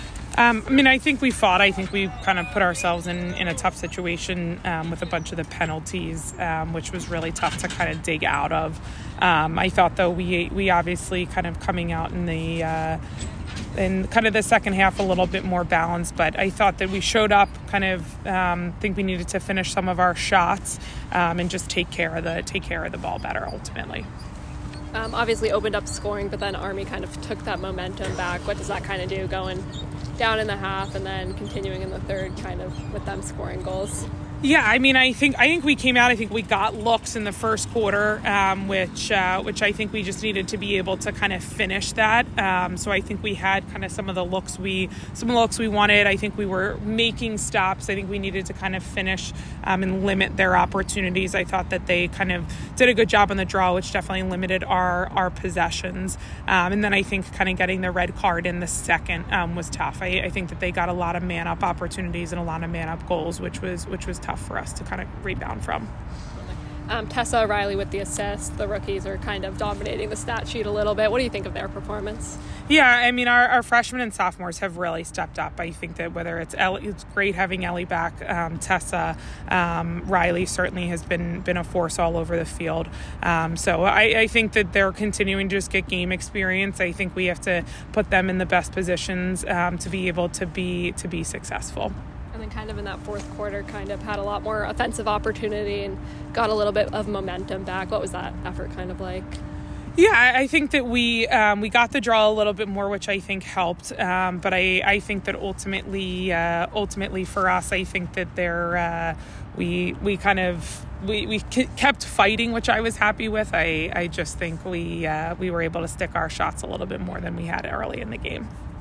Army West Point Postgame Interview